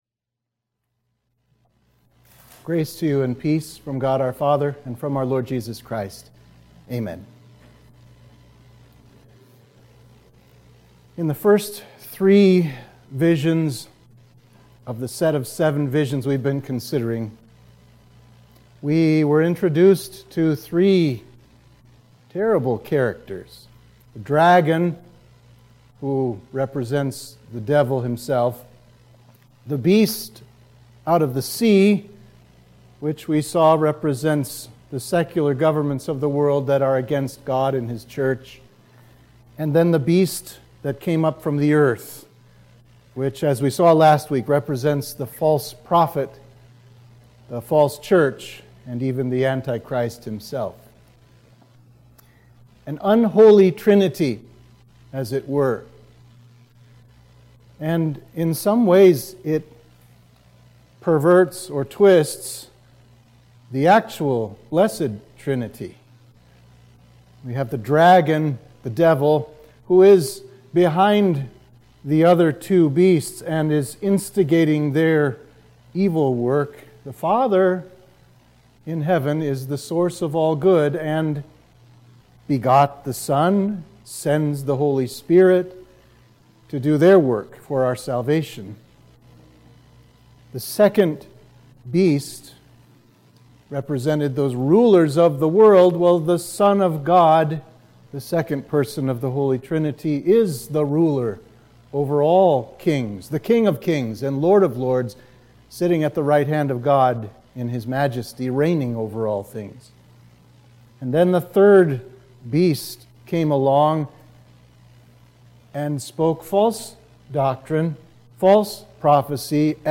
Sermon for Midweek of Trinity 7